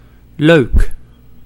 Ääntäminen
IPA: /lø:k/